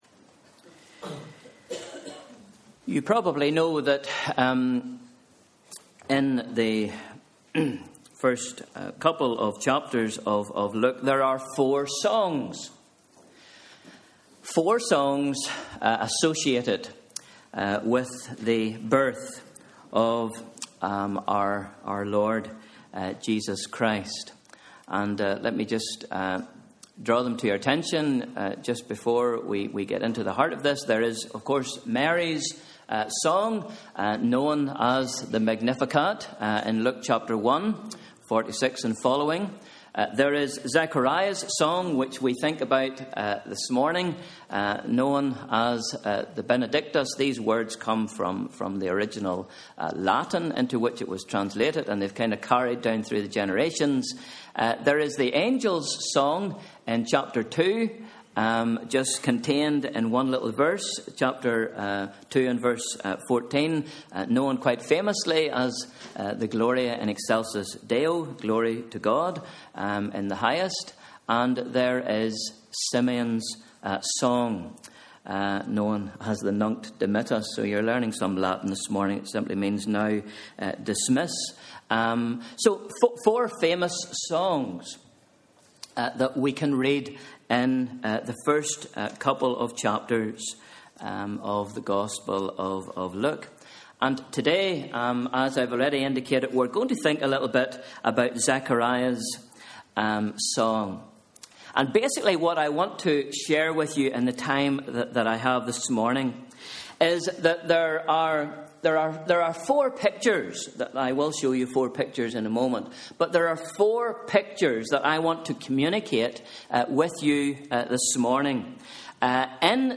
Sunday 15th December 2013 – Morning Service